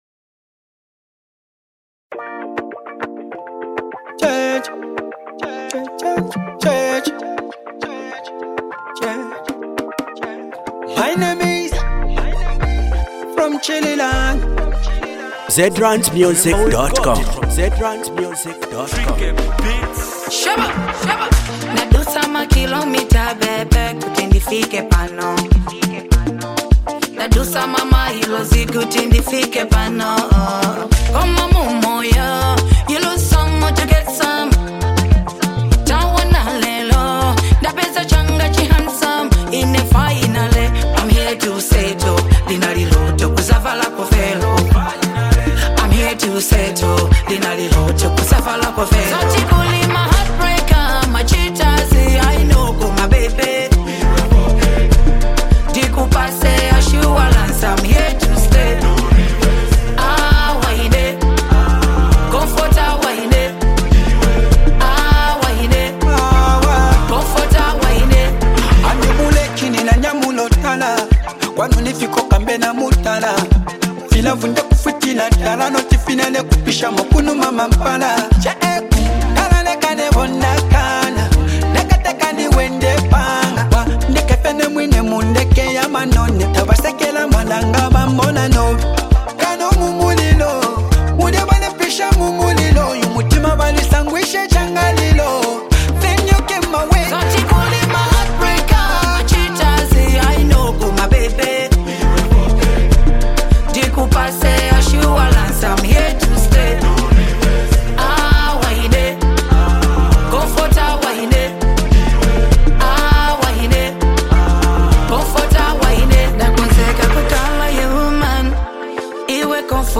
commanding vocals
smooth delivery